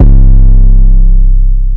808 1 [ sizzle go to ].wav